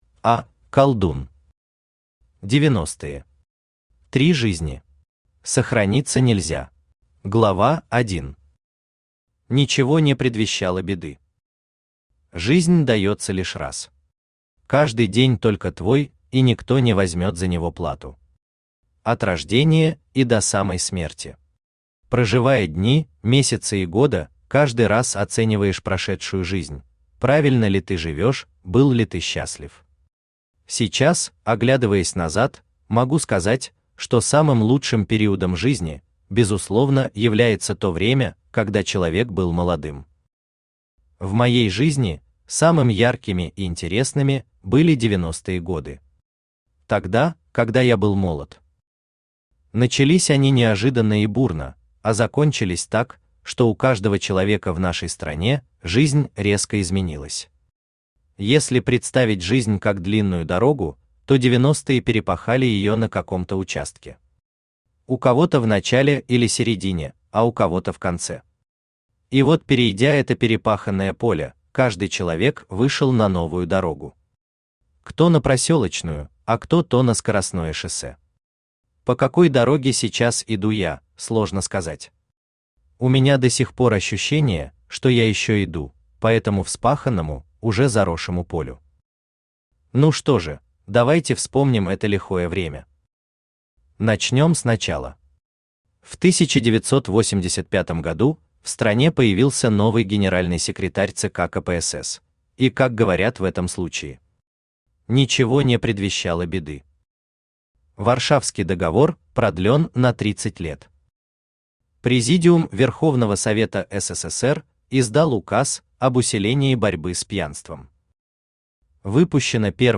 Аудиокнига 90-е. Три жизни. Сохраниться нельзя | Библиотека аудиокниг
Сохраниться нельзя Автор А. В. Колдун Читает аудиокнигу Авточтец ЛитРес.